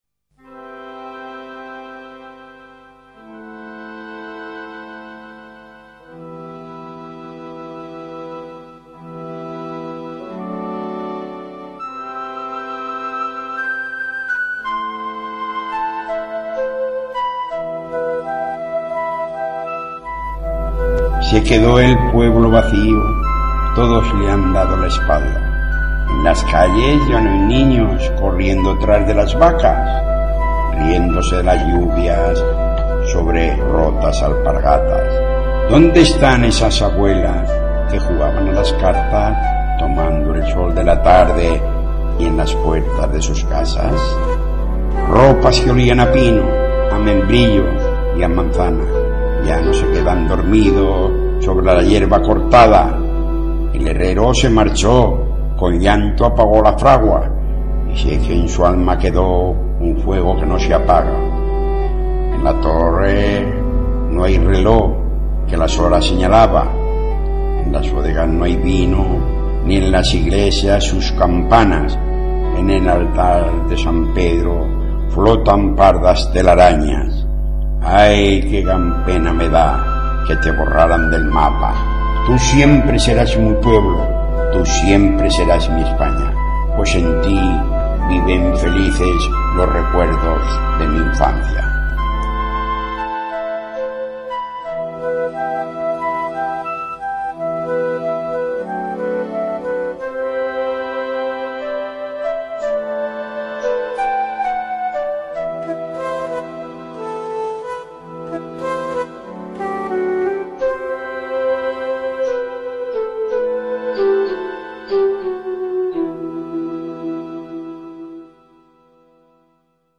Pueblo (rapsoda y autor de la letra